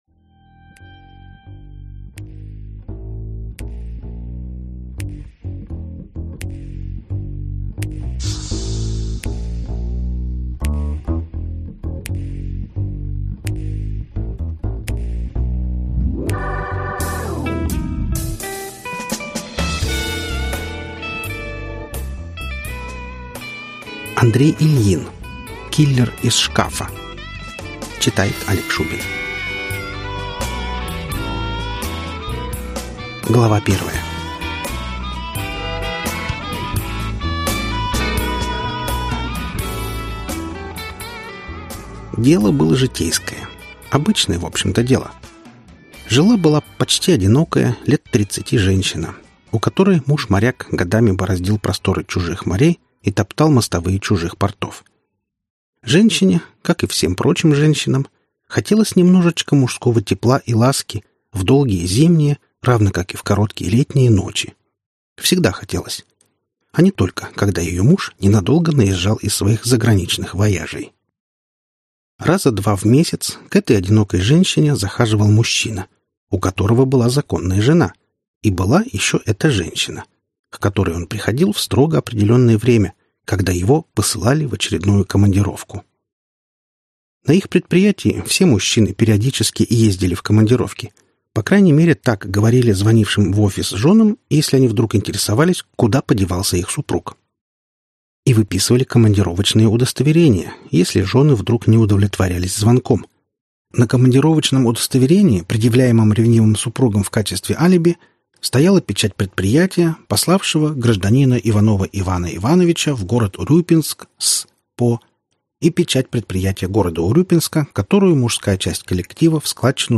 Аудиокнига Киллер из шкафа | Библиотека аудиокниг